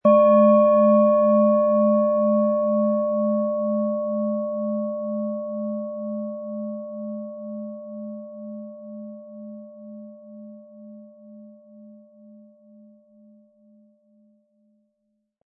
Planetenschale® Öffnet für Träume & Inspiriert fühlen mit Neptun, Ø 12,9 cm, 180-260 Gramm inkl. Klöppel
Planetenton 1
Um den Original-Klang genau dieser Schale zu hören, lassen Sie bitte den hinterlegten Sound abspielen.
SchalenformBihar
MaterialBronze